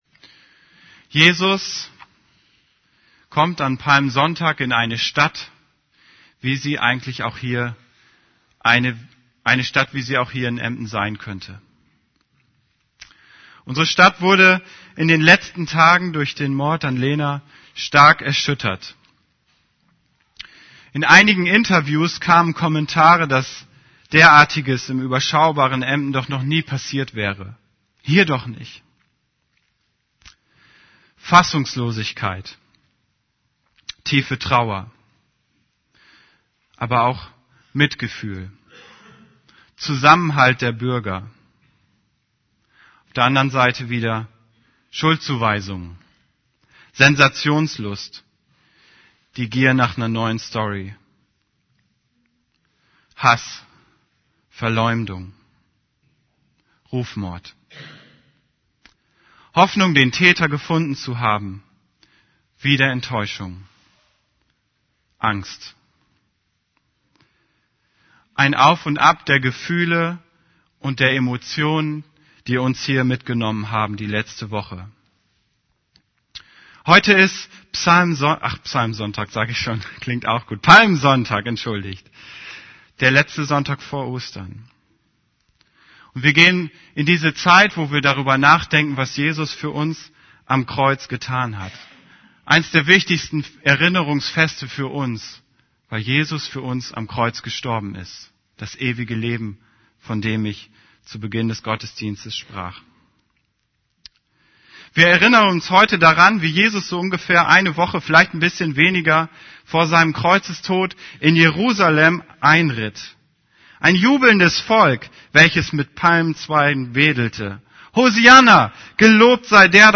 > Übersicht Predigten Gott gibt Kraft für den Weg Predigt vom 01. April 2012 Predigt Predigttext: Markus 11, 1-11 1 Kurz vor Jerusalem kamen Jesus und die Jünger zu den Ortschaften Betfage und Betanien am Ölberg.